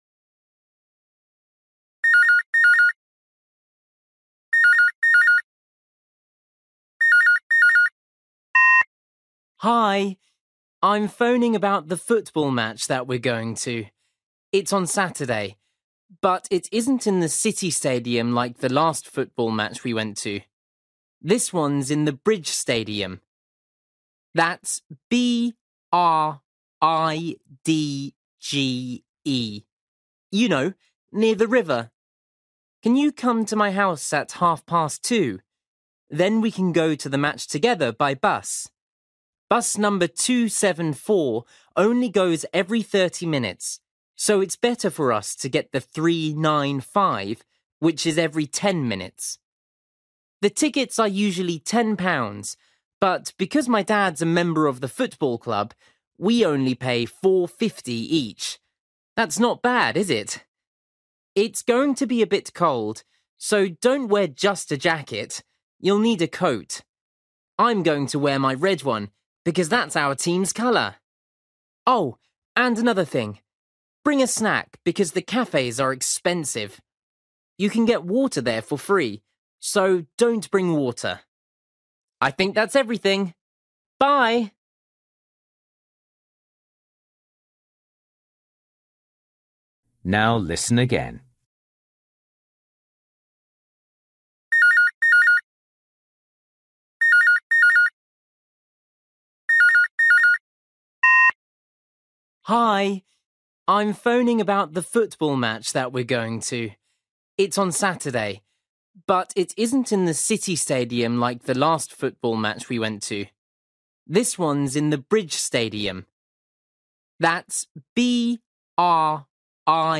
You will hear a boy leaving a message for a friend about a football match.